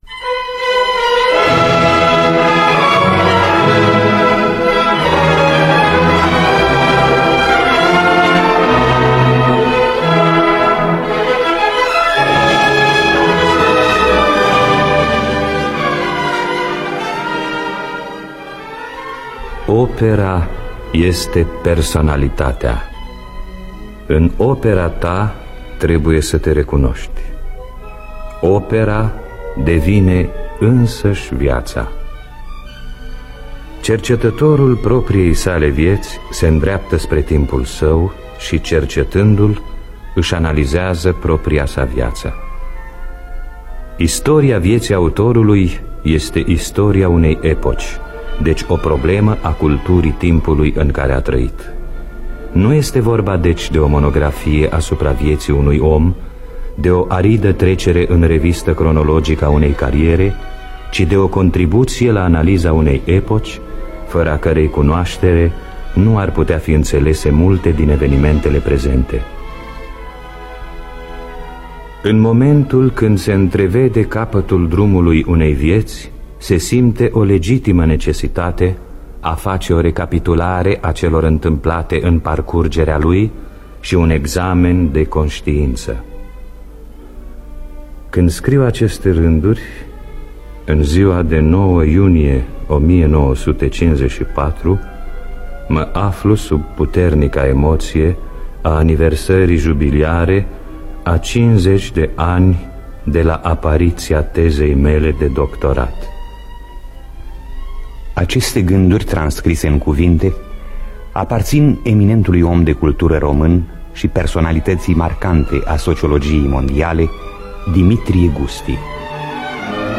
Scenariu radiofonic de Neculai Moghior.